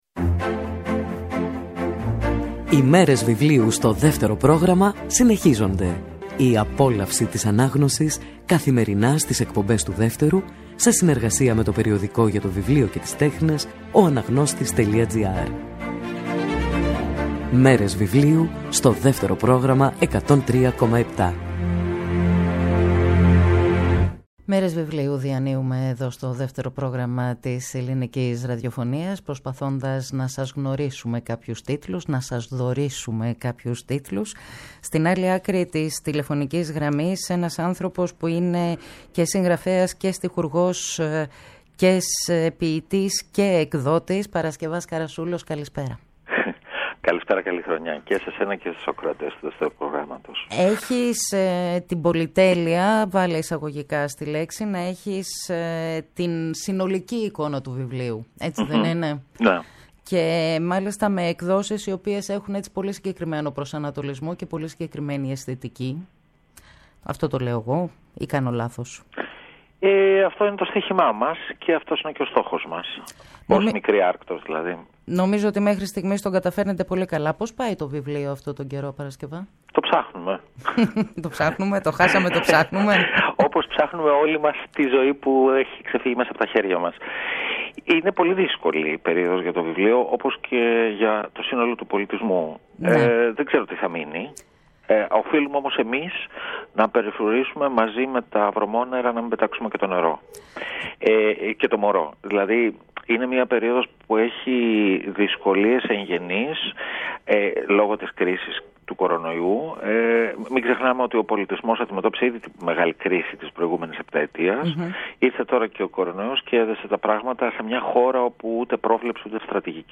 σε μια συζήτηση για «Λογοτεχνία και μουσική».